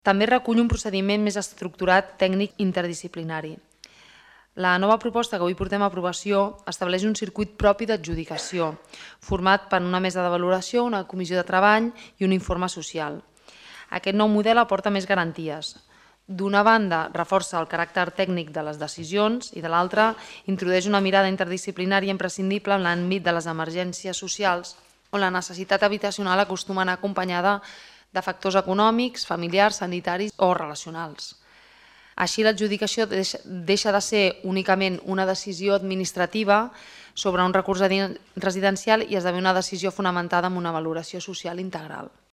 Cristina Dalmau, regidora Benestar Social